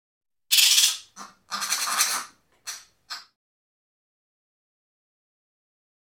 Monkey, Capuchin Chatter. High Pitched Chirping Sound. Medium Perspective.